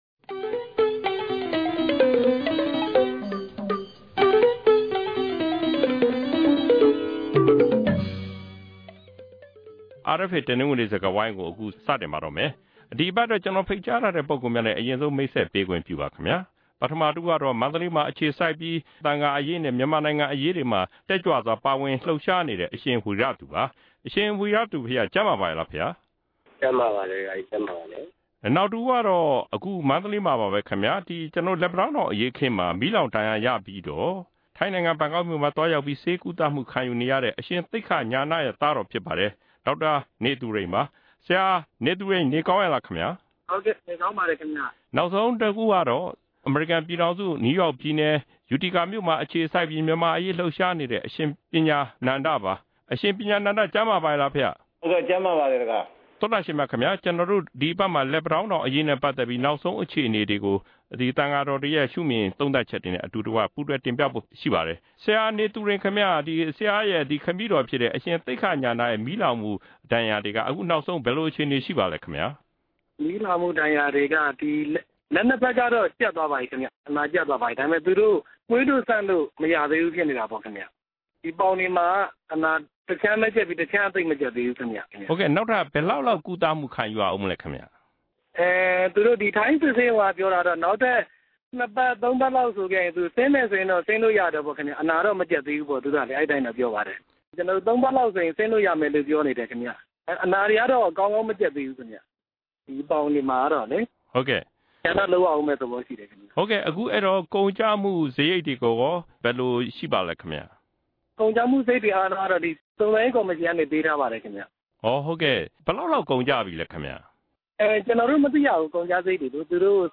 ကြေးနီတောင်အရေးမှာ မီးလောင်ဒဏ်ရာရ သံဃာတော်တွေနဲ့ ဆွေးနွေးချက်